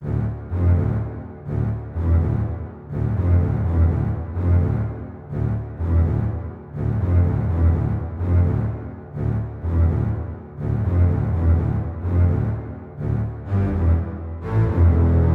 Tag: 125 bpm Cinematic Loops Strings Loops 2.58 MB wav Key : D